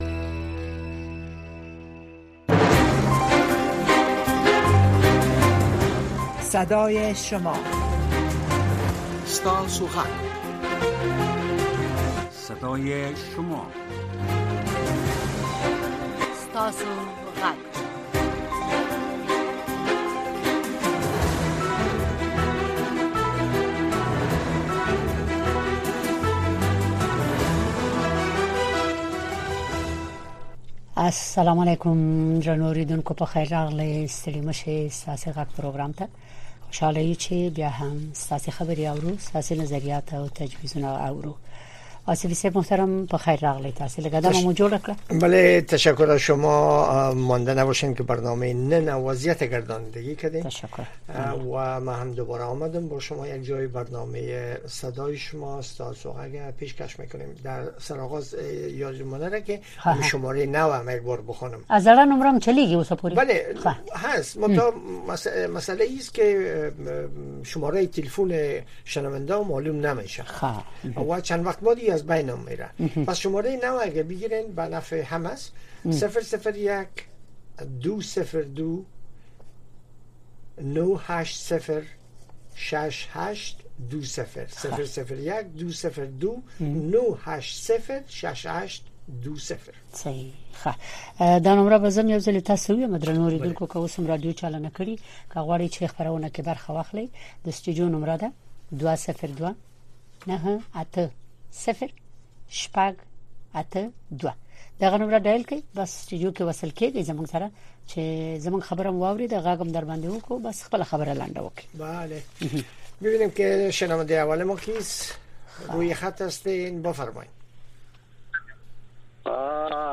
در برنامۀ صدای شما شنوندگان رادیو آشنا صدای امریکا به گونۀ مستقیم با ما به تماس شده و نظریات، نگرانی‌ها، دیدگاه، انتقادات و شکایات شان را با گردانندگان و شنوندگان این برنامه در میان می‌گذارند. این برنامه به گونۀ زنده از ساعت ۱۰:۰۰ تا ۱۰:۳۰ شب به وقت افغانستان نشر می‌شود.